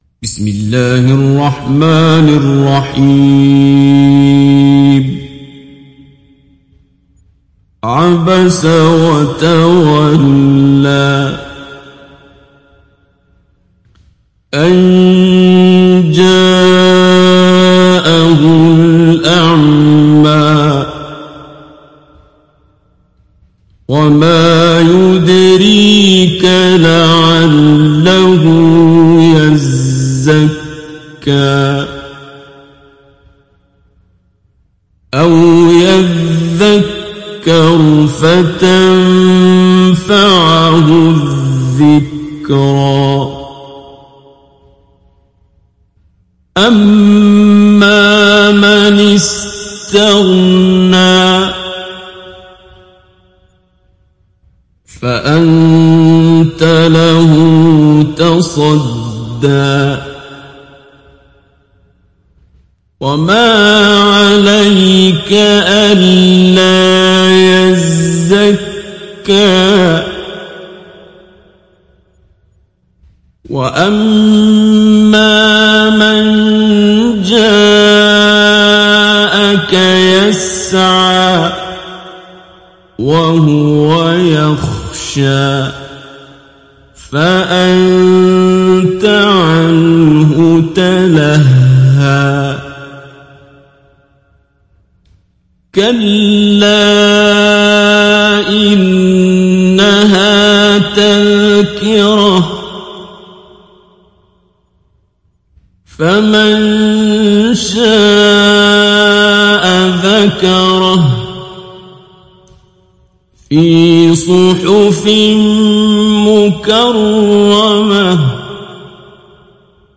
تحميل سورة عبس mp3 عبد الباسط عبد الصمد مجود (رواية حفص)